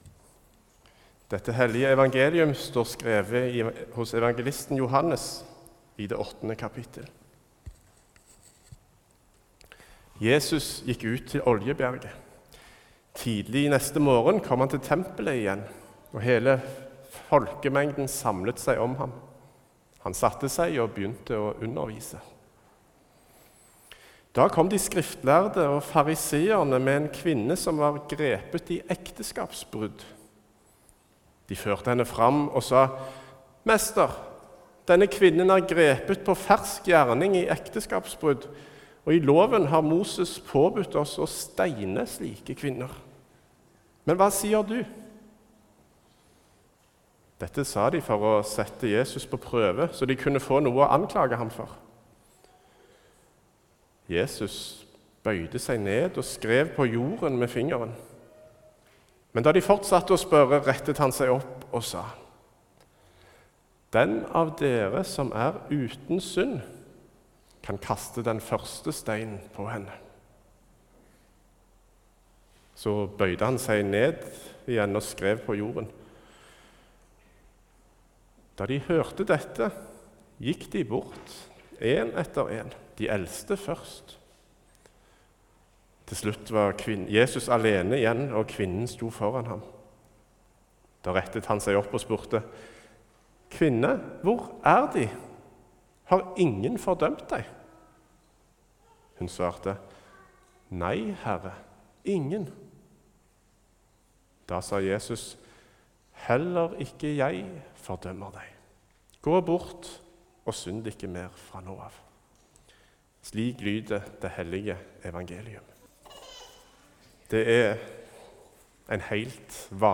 Preken 4.oktober som lydfil
Her kan du høre prekenen holdt i Hinna kirke 4.okt.